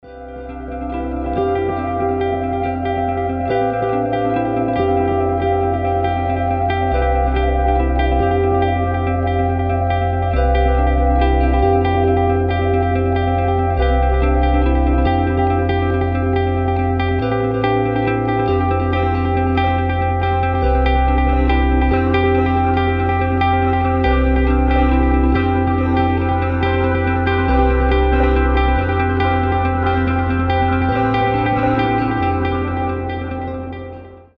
Тихая, ненавязчивая музыка на будильник